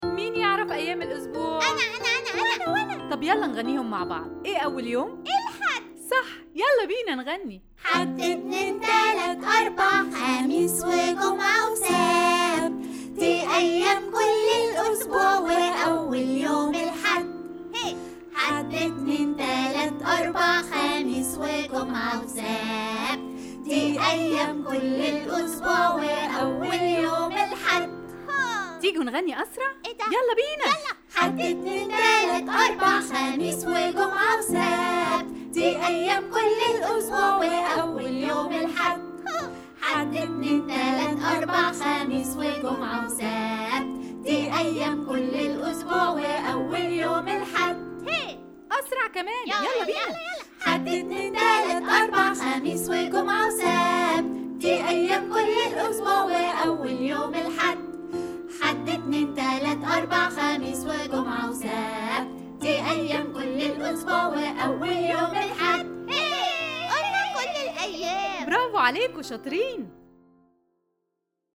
Their catchy rhythm and use of repetition gently boost their memory and vocabulary.